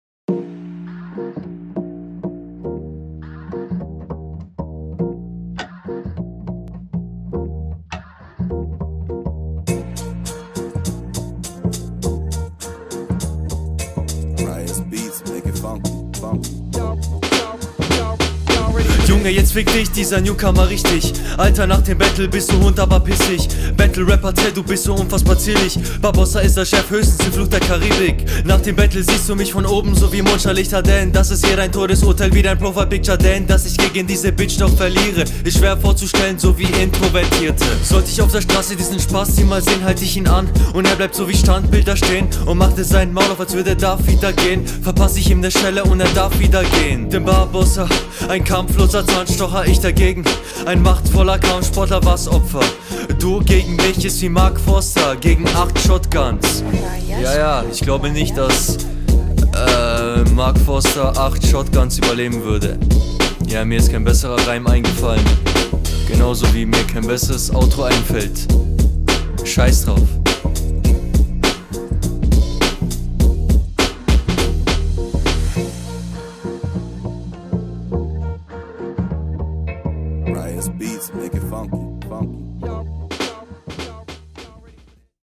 Flow geht für Bronze klar.
Der Flow ist leider an vielen Stellen bisschen raus und vorallem die Aussprache leidet hier …